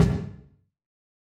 KICK UNCUT.wav